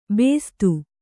♪ bēstu